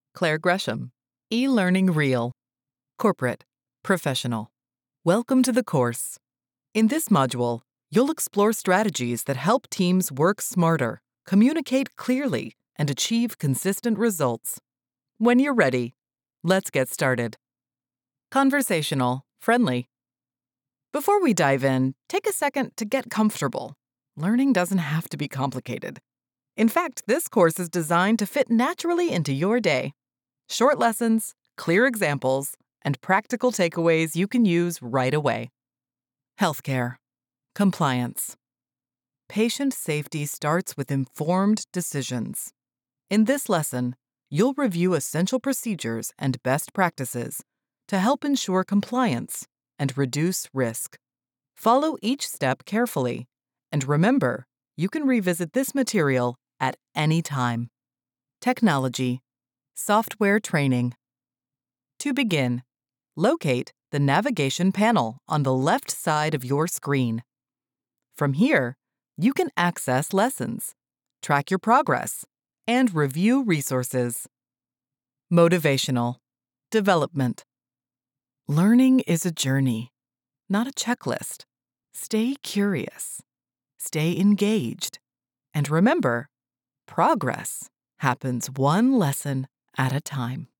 eLearning Reel
General American, US Southern (various dialects), British RP
Middle Aged